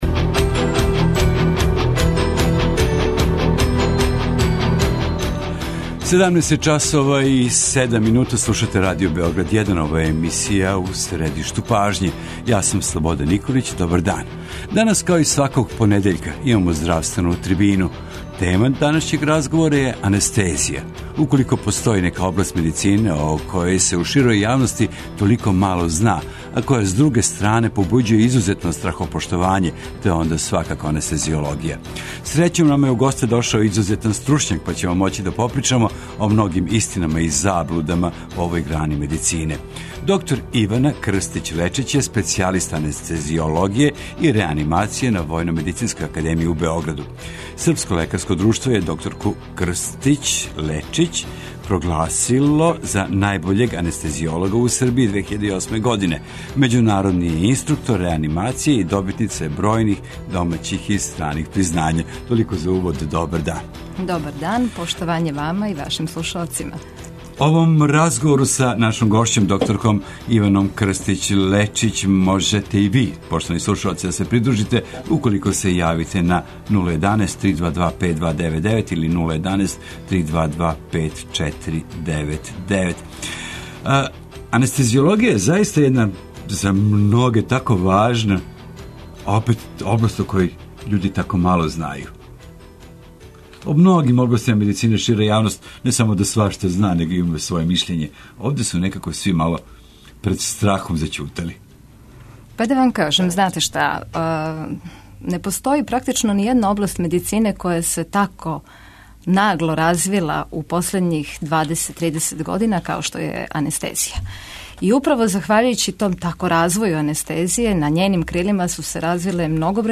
Гошћа емисије одговараће на многа занимљива питања везана за анестезије и терапију бола. Зашто се људи понекад више плаше анестезије него саме операције?